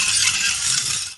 metal_scrape_1.wav